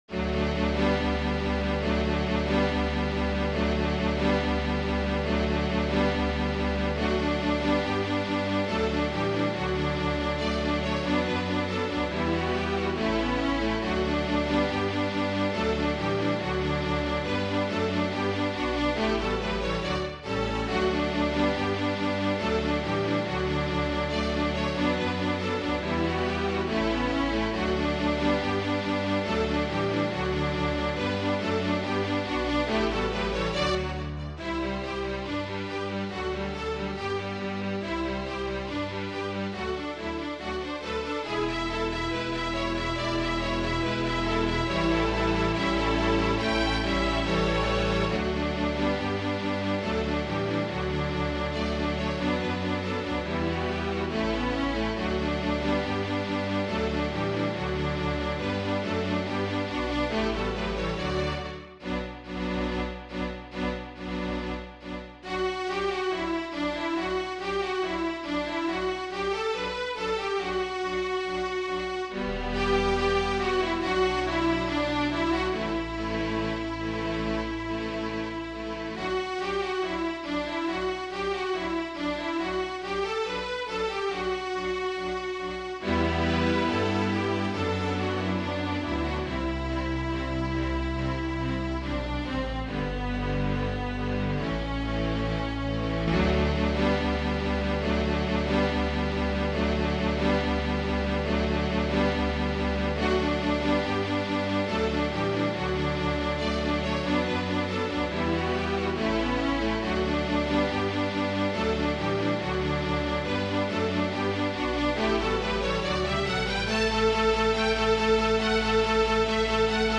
Style: Neoclassical
Standard String Orchestra